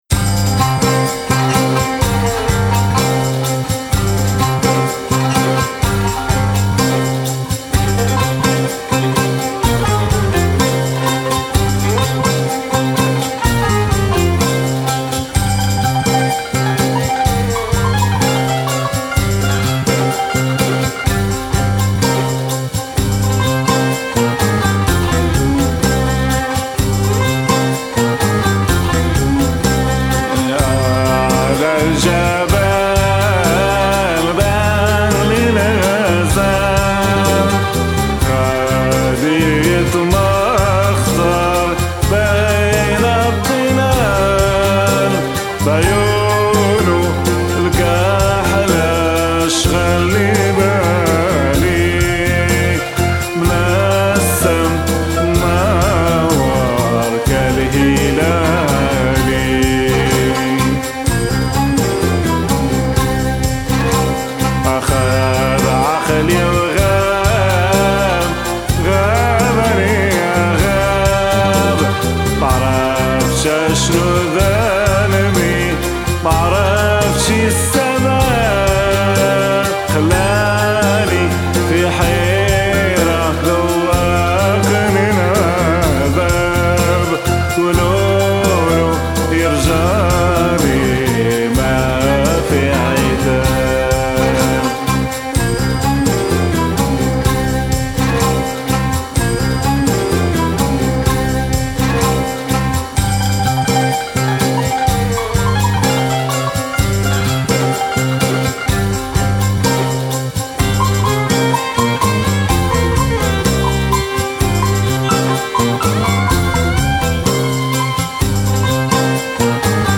アラブ＝アンダルース音楽をカントリー・ロック化したような不思議な音楽性？意外と癖になるＰＯＰな魅力を放ってますよ！